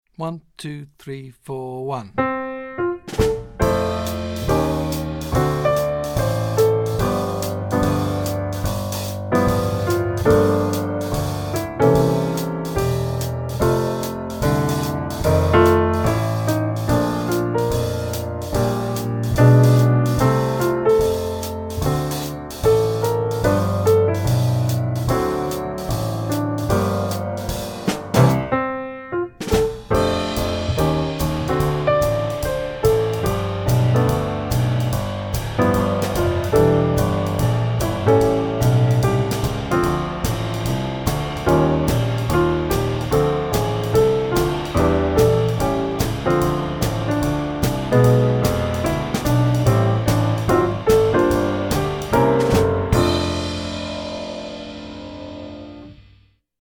30-Indiana-melody-with-two-handed-chords.mp3